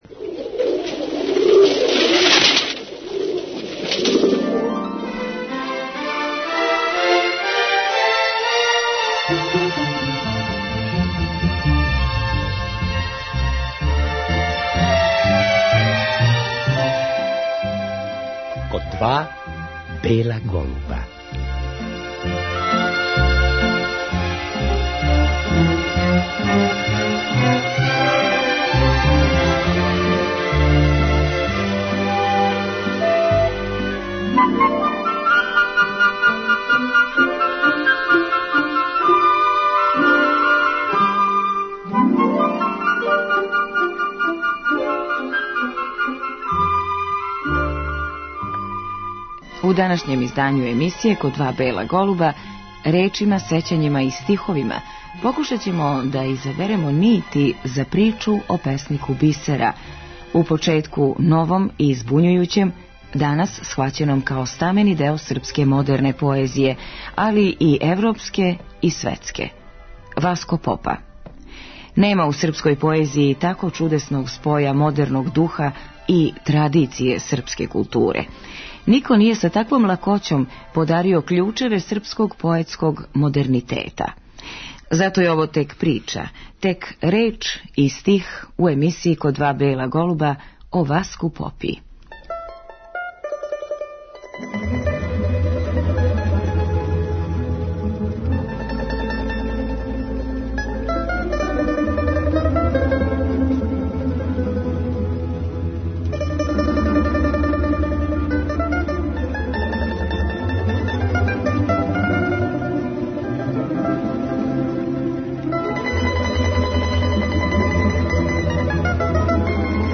У емисији ћемо чути и глас самог песника који казује своје стихове.